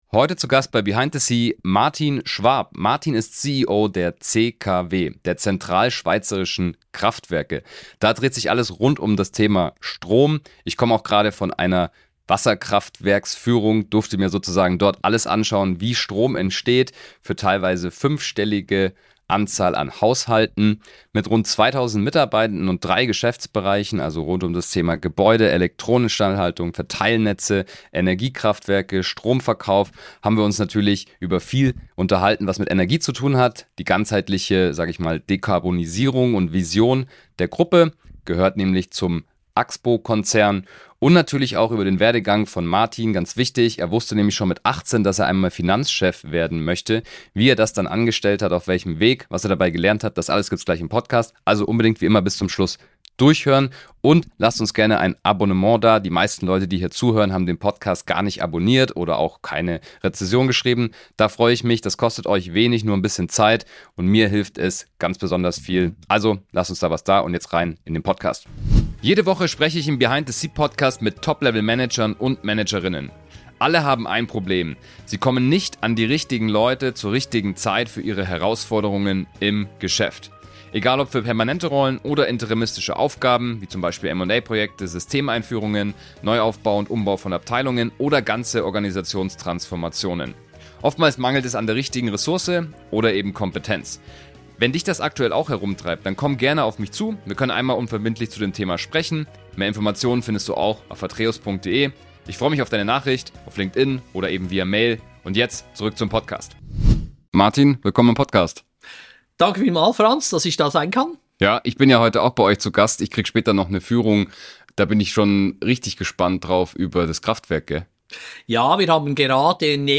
Das Gespräch behandelt Themen wie klare Zielorientierung, die Kraft von effektivem Mentoring, flexible Führungsansätze und die Bedeutung von Alltagsroutinen in einem hochdynamischen Umfeld.